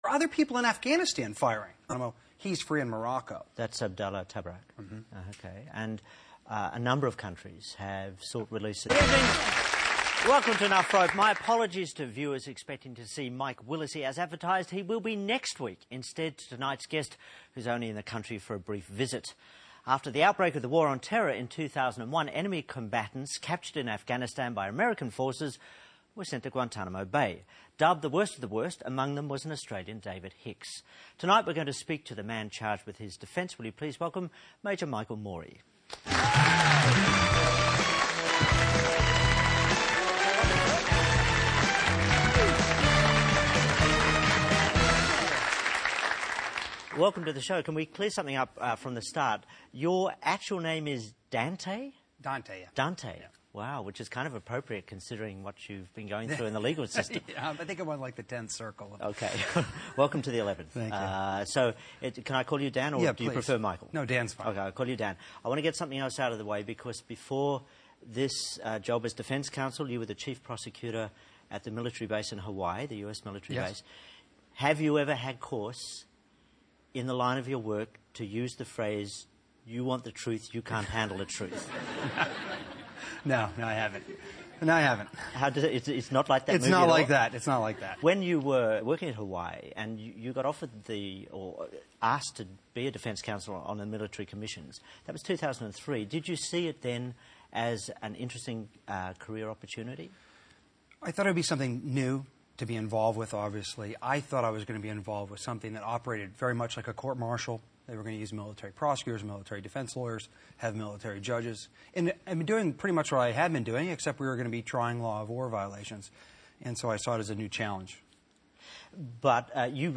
Watch the Andrew Denton interview with Australia's David Hicks' attorney, Major Michael Mori .